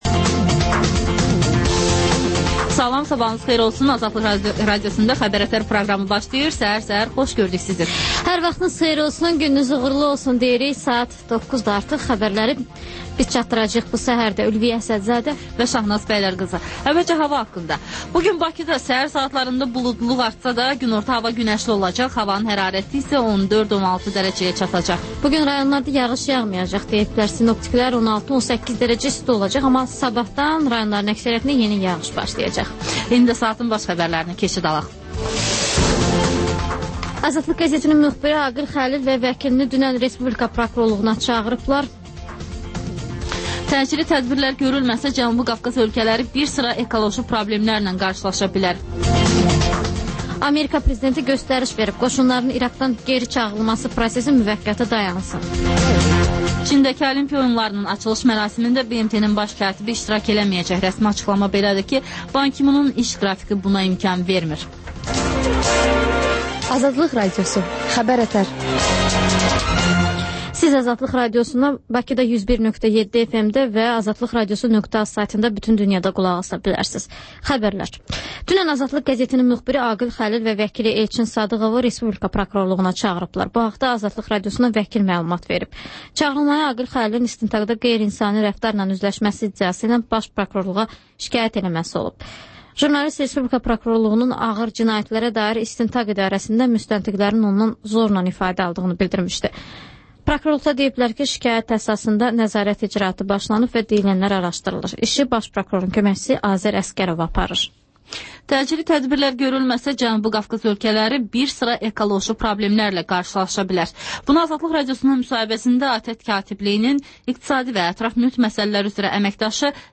Xəbər-ətər: xəbərlər, müsahibələr, sonra 14-24: Gənclər üçün xüsusi veriliş